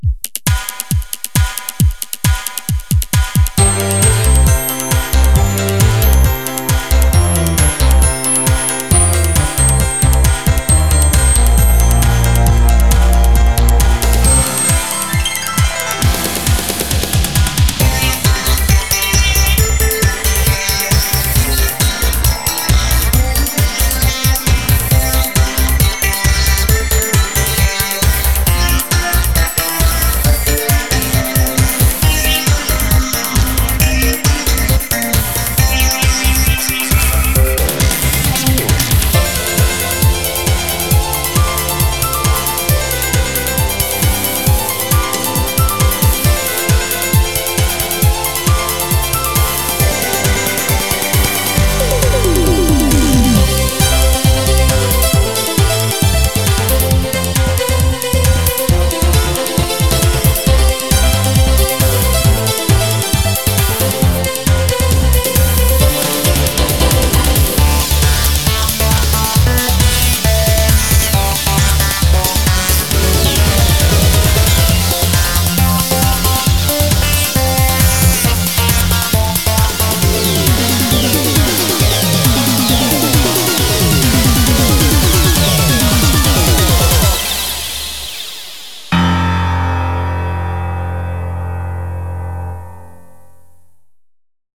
BPM135
Audio QualityPerfect (High Quality)
Better quality audio.